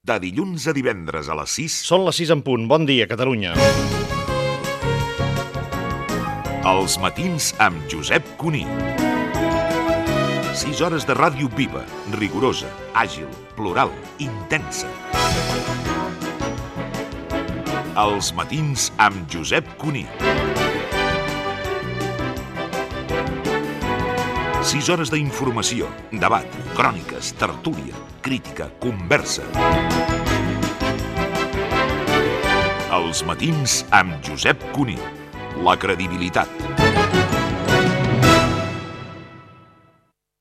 Promoció del programa.
Info-entreteniment
Programa presentat per Josep Cuní.